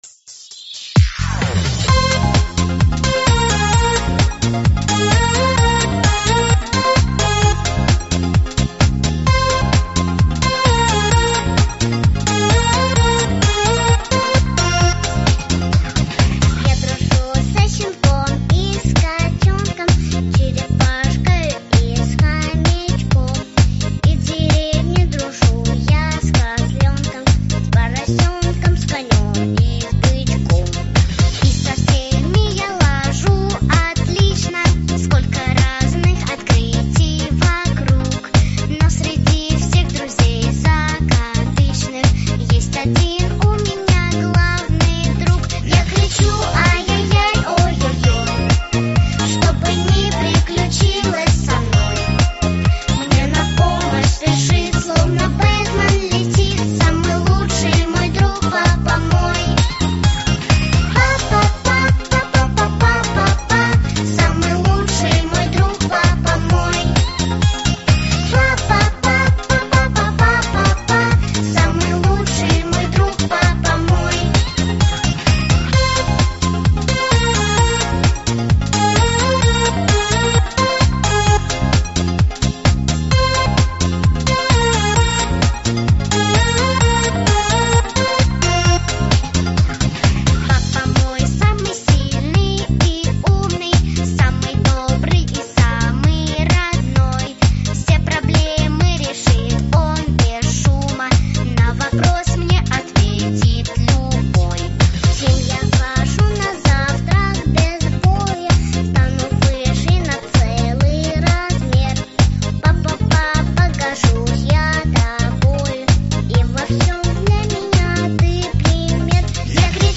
ВЕСЁЛАЯ ДОБРАЯ ПЕСЕНКА ДЛЯ МАЛЫШЕЙ и ДЕТЕЙ 0+